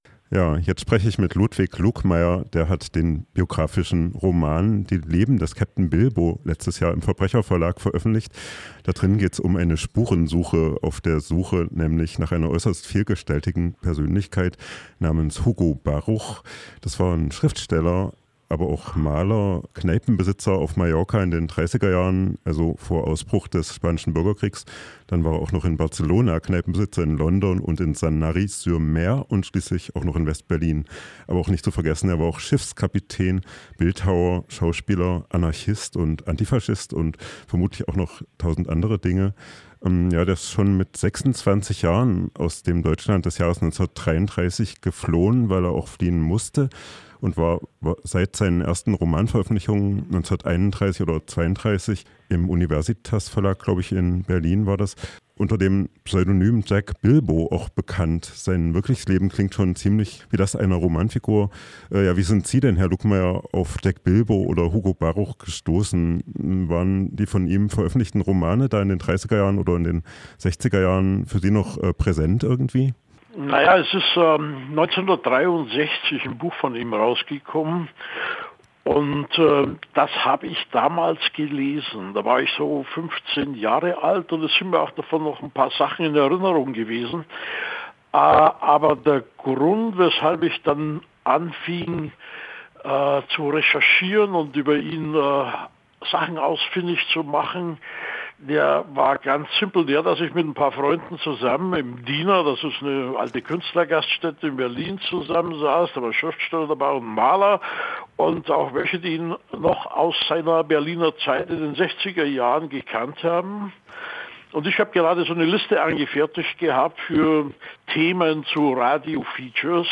Beginnen wir mal mit einem Interview mit Patrick Breyer vom Arbeitskreis Vorratsdatenspeicherung.